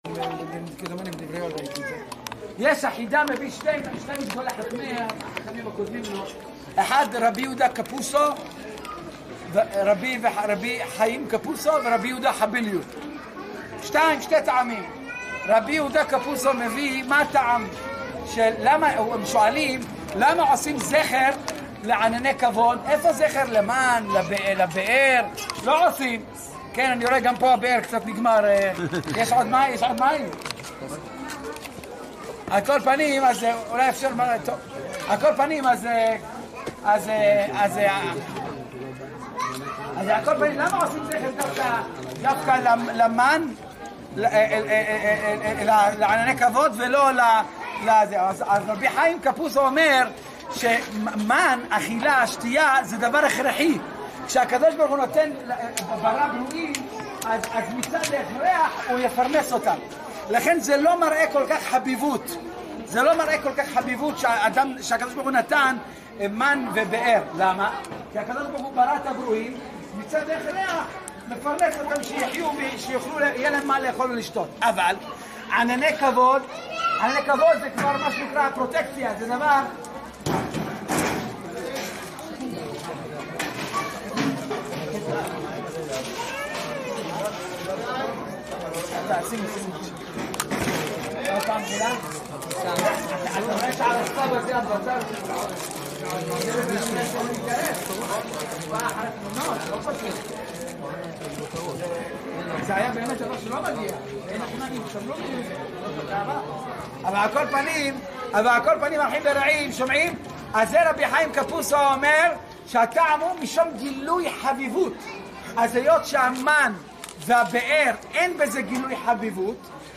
סוכות ה'תשעט